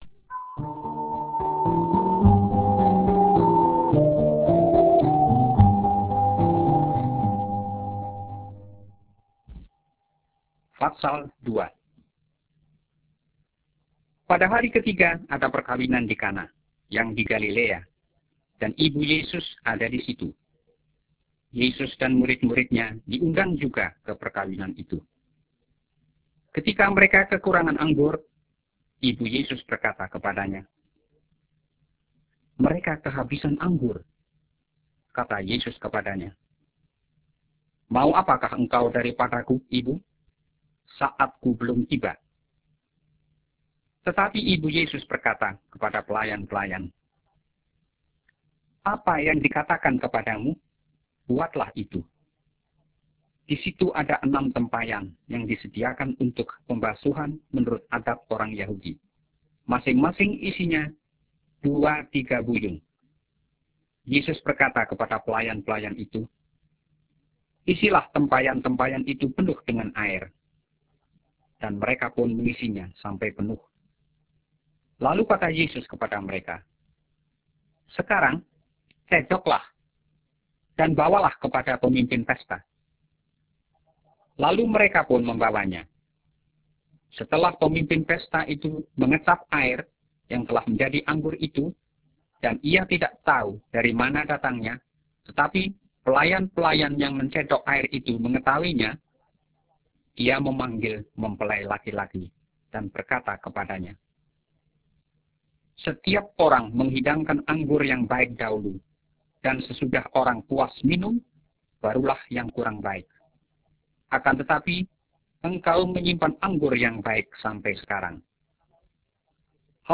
Alkitab AUDIO -- Yohanes 02